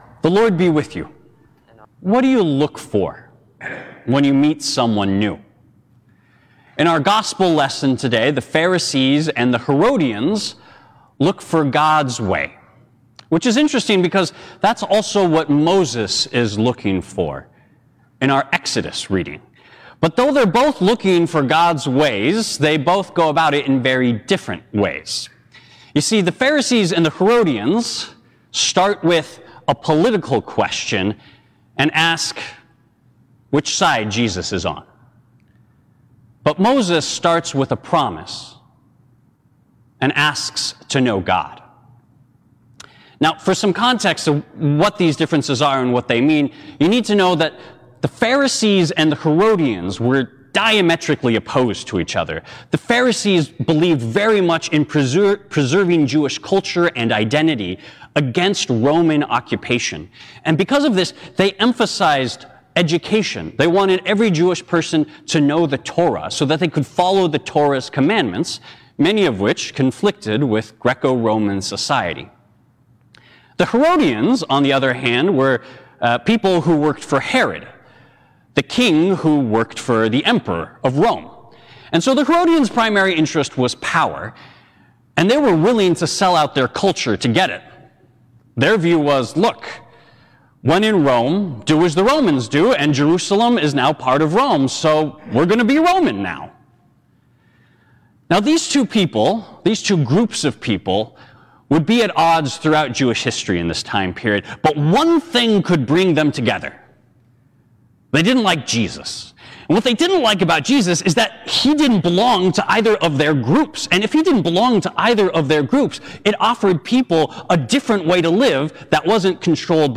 Sermons from Faith Lutheran Church | Faith Lutheran Church